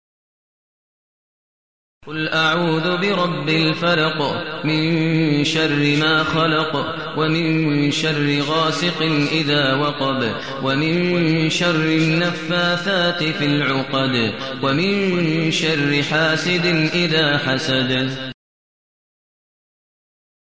المكان: المسجد الحرام الشيخ: فضيلة الشيخ ماهر المعيقلي فضيلة الشيخ ماهر المعيقلي الفلق